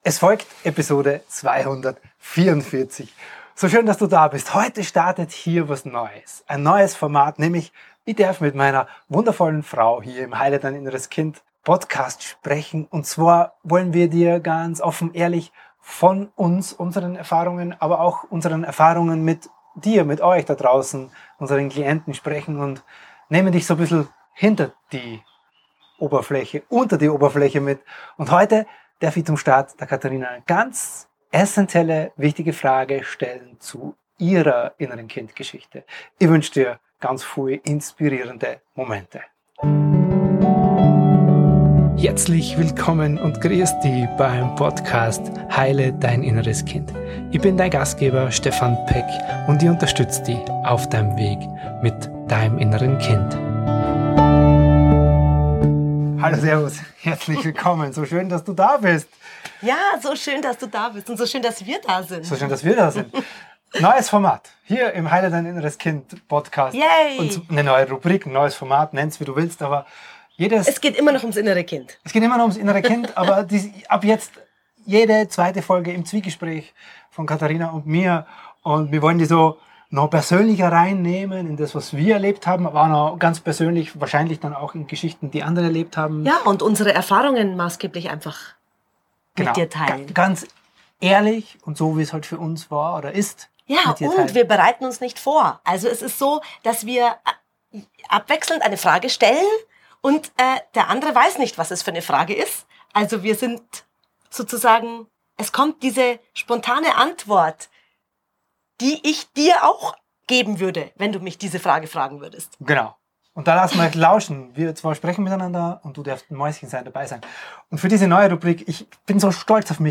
Offen, ungefiltert, ohne Skript.